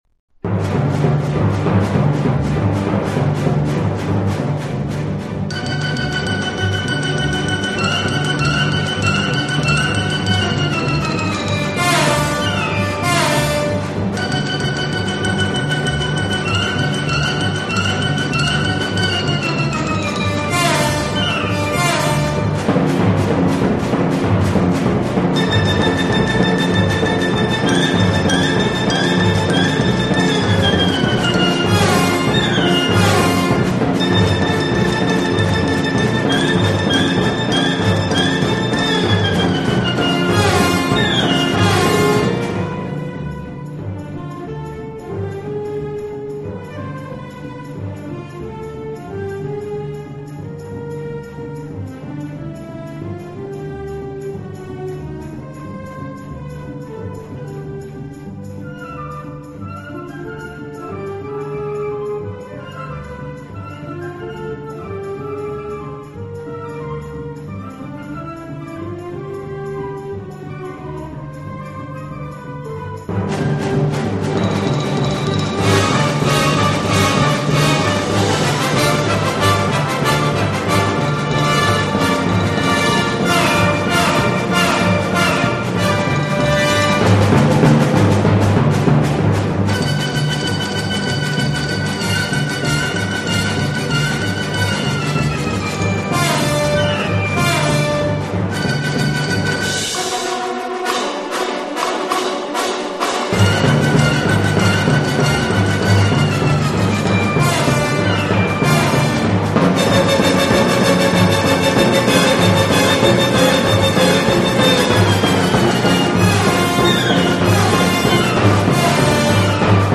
Genre: Classical
Channels: 44Khz, stereo